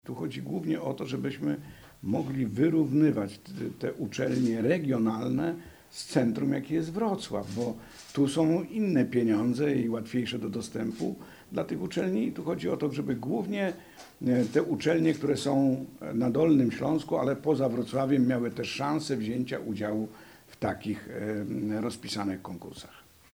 Jednym z celów programu jest wzmocnienie ośrodków akademickich poza stolicą regionu – mówi Jerzy Pokój, przewodniczący Sejmiku Województwa Dolnośląskiego.